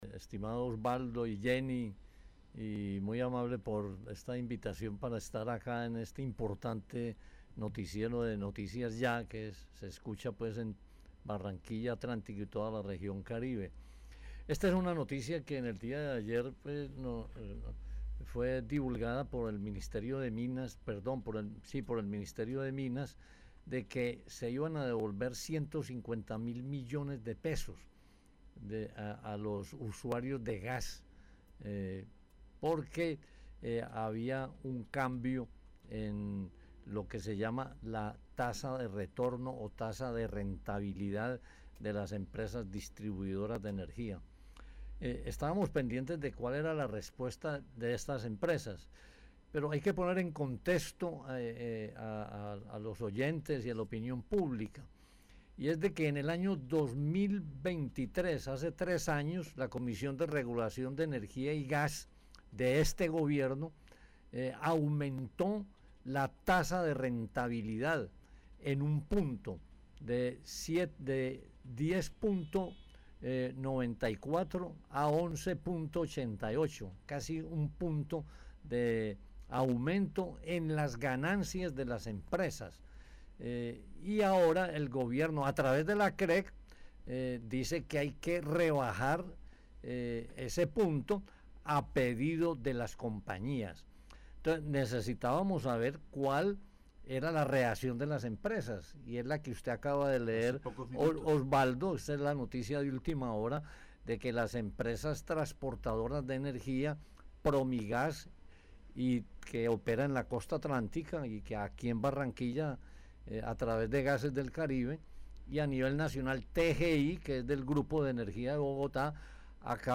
En entrevista con Noticias Ya